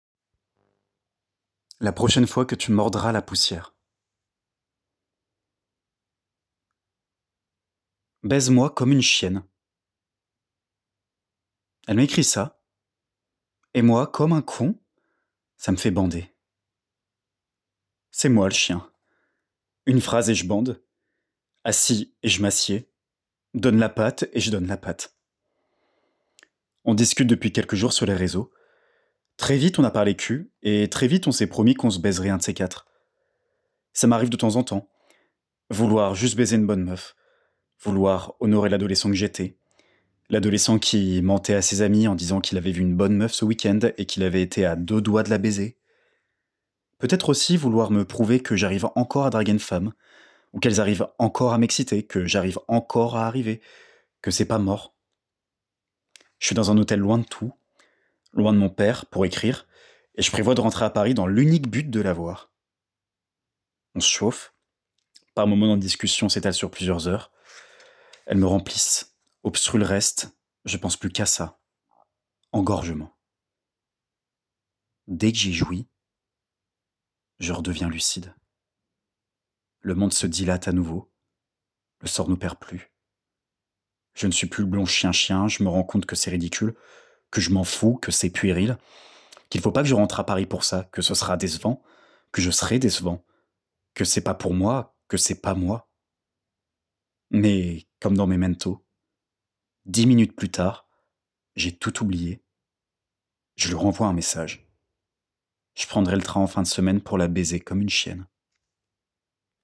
Voix off
21 - 35 ans - Basse